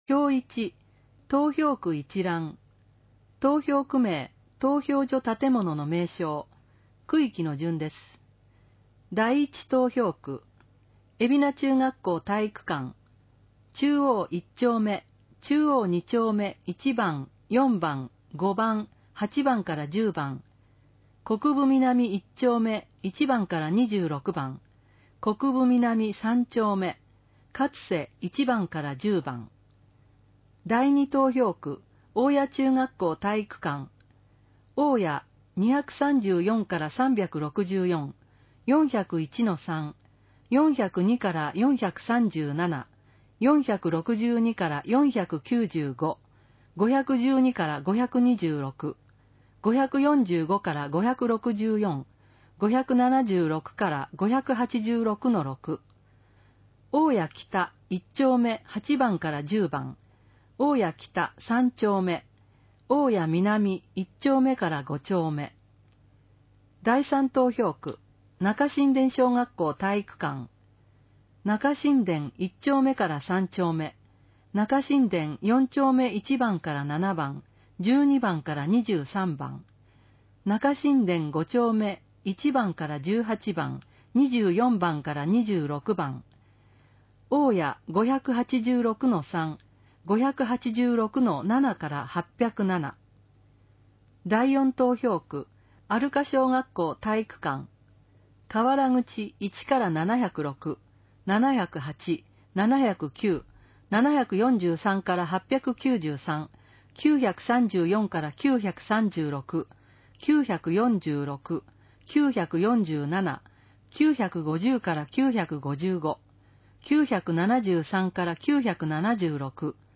音声版は、音声訳ボランティア「矢ぐるまの会」のご協力により、同会が視覚障がい者の方のために作成したものを登載しています